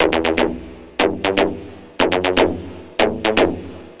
TSNRG2 Lead 007.wav